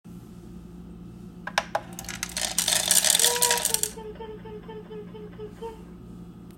This gadget allows you to record up to a 10 second sound clip that plays each time you hit “feed.” How neat is that?
Here is a recording I made for you from our own feeder: